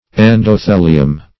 Endothelium \En`do*the"li*um\, n.; pl.